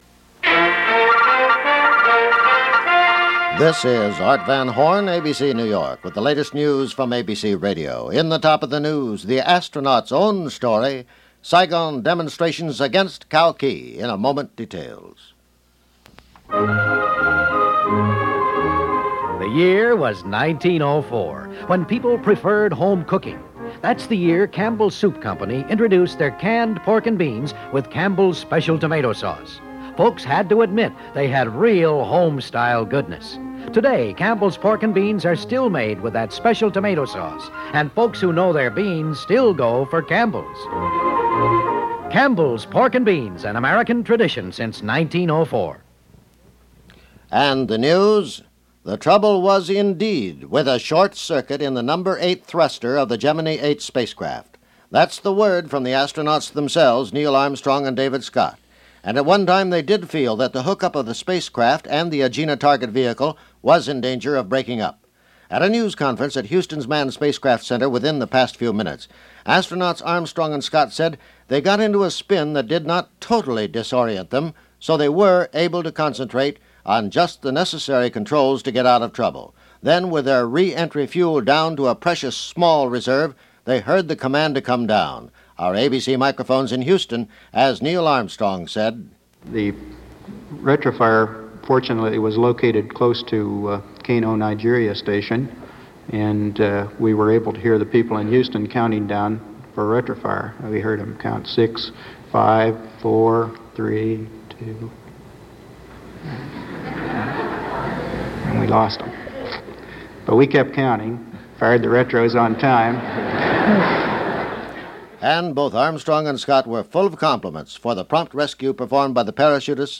March 26, 1966 – ABC Radio News – Gordon Skene Sound Collection –
And that’s a little of what happened, this March 26, 1966 as reported by ABC Radio News On The Hour.